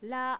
Japanese consonants are pronounced about the same as English consonants.